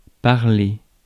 Ääntäminen
France (Paris): IPA: [paʁ.le]